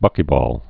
(bŭkē-bôl)